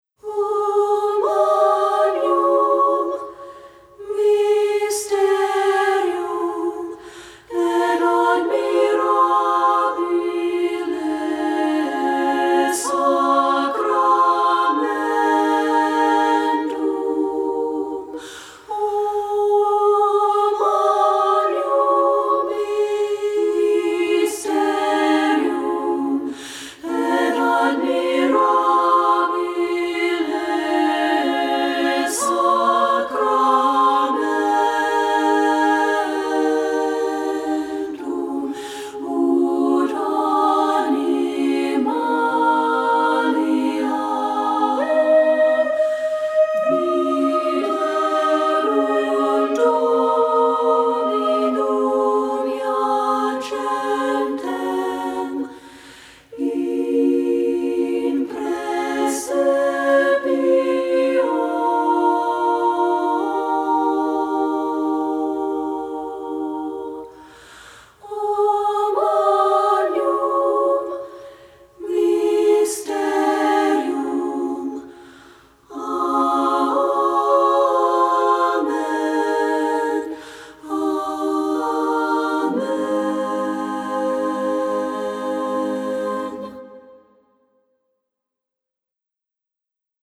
SSA Voices A Cappella
• Soprano
• Alto
Studio Recording
Absolutely beautiful for women's a cappella voices
Ensemble: Treble Chorus
Accompanied: A cappella